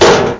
klonk4.mp3